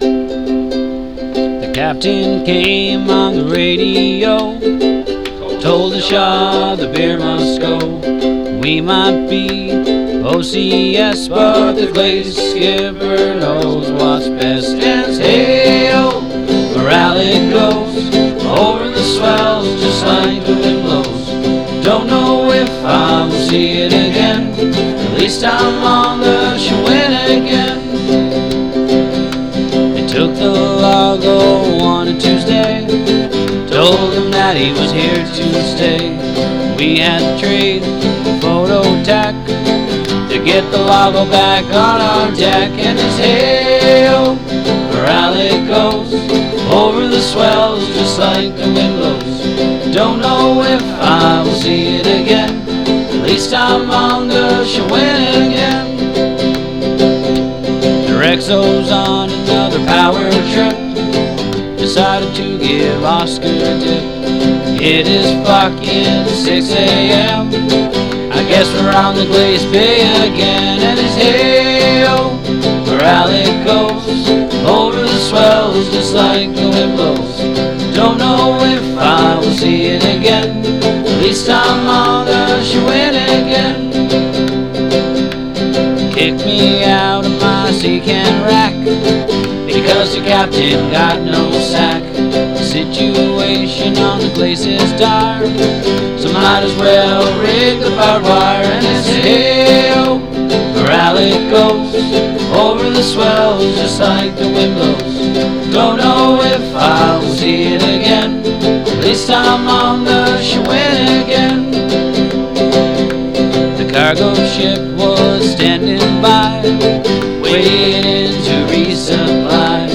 Shawinigan Pride - Recorded in the ET Workshop by some of the junior ranks on HMCS SHAWINIGAN during Op Projection 2020